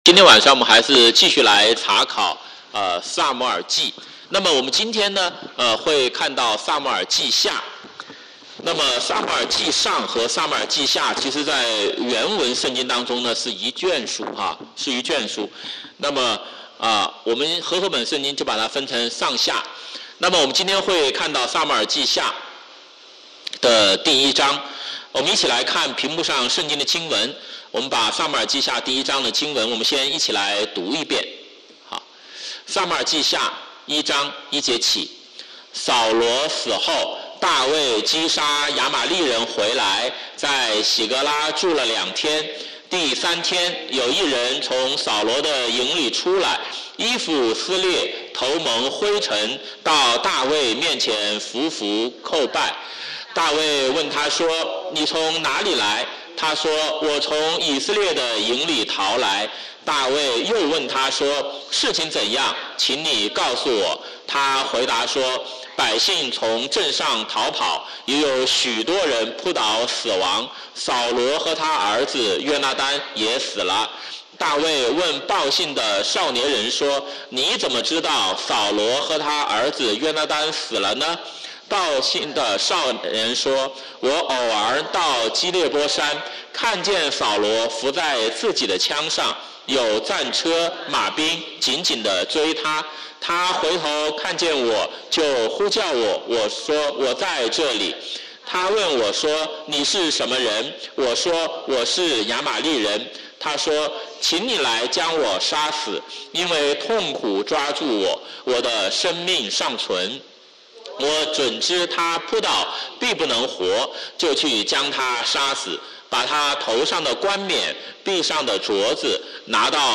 Friday Night Bible Study